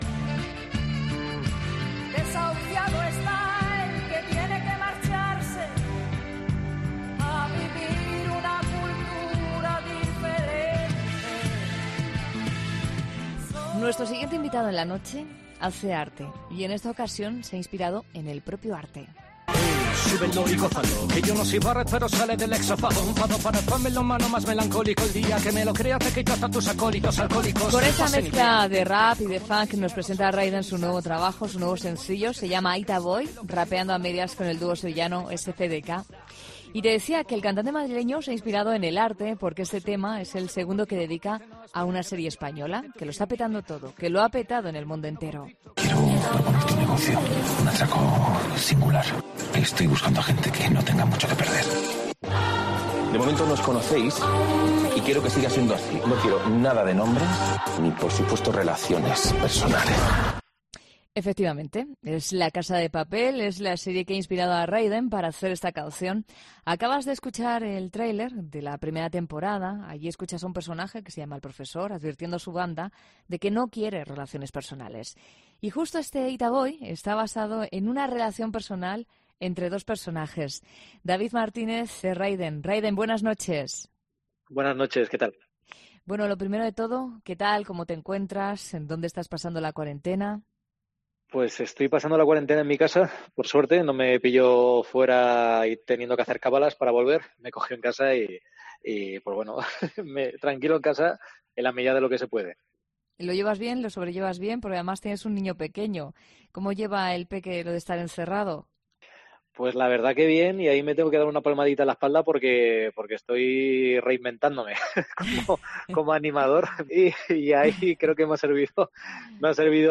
El cantante madrileño nos cuenta cómo está viviendo la cuarentena y nos habla de su nuevo sencillo llamado Itaboy!